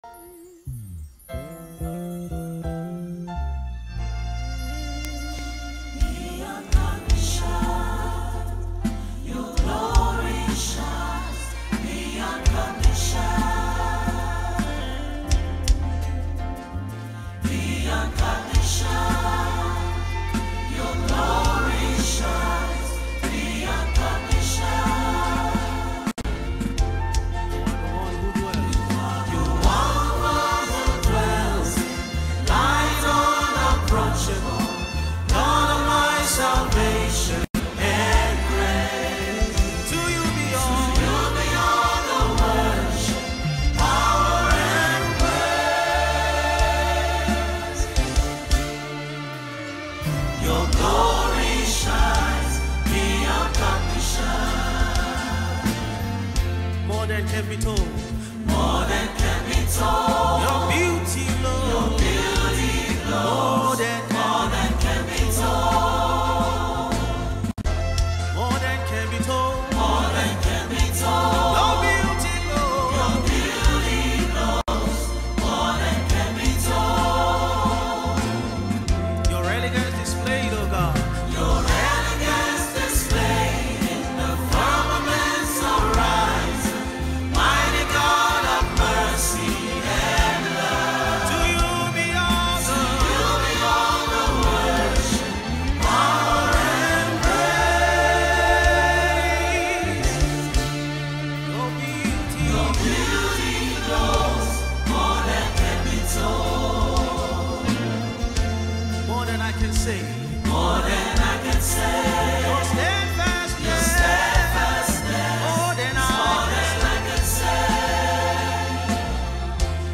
February 11, 2025 Publisher 01 Gospel 0